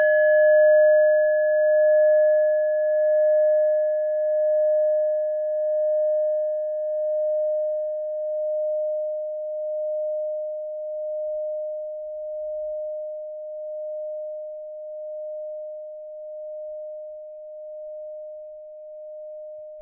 Klangschale Nepal Nr.40
Klangschale-Gewicht: 950g
Klangschale-Durchmesser: 14,1cm
(Ermittelt mit dem Filzklöppel)
klangschale-nepal-40.wav